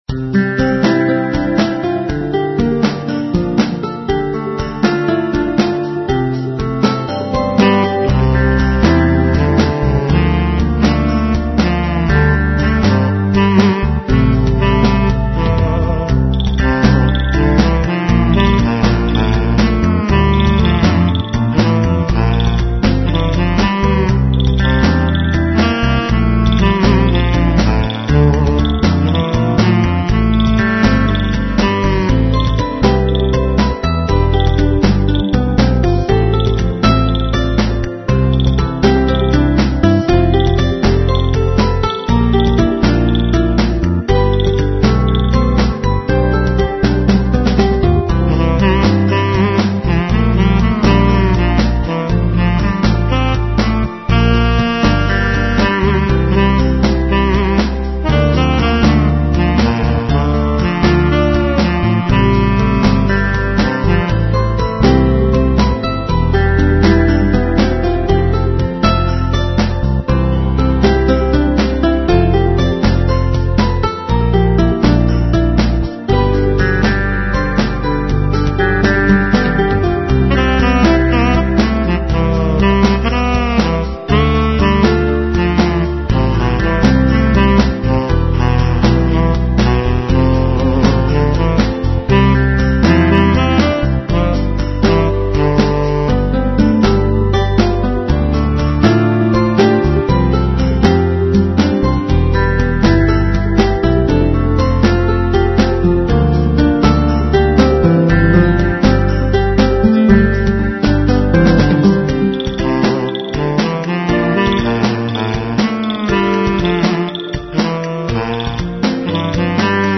Playful Instrumental Pop with Synth Saxophone lead